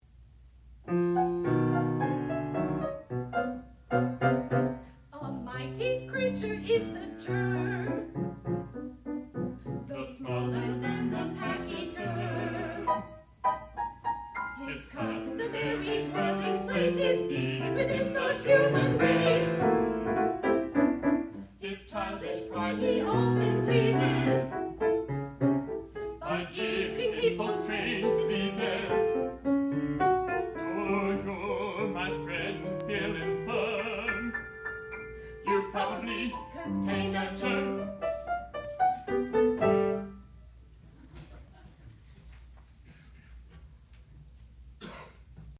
sound recording-musical
classical music
soprano
piano
baritone